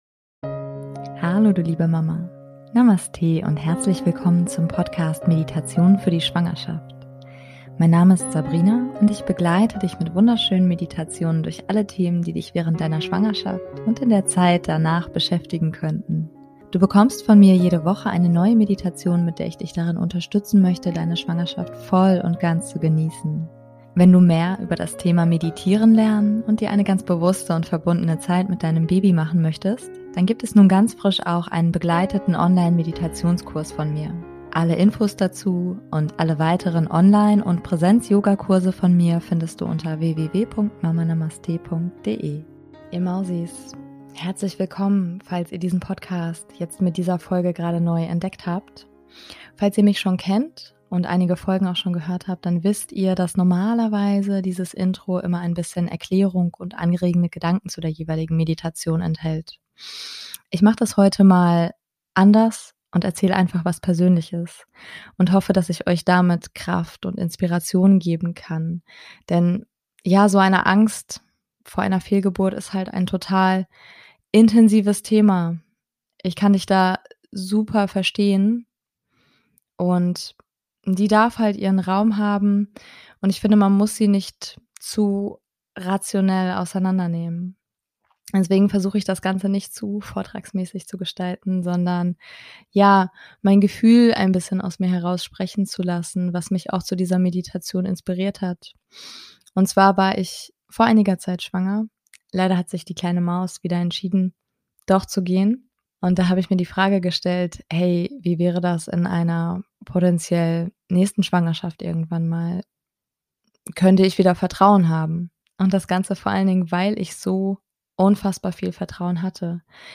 #098 - Angst vor einer (erneuten) Fehlgeburt - Meditation ~ Meditationen für die Schwangerschaft und Geburt - mama.namaste Podcast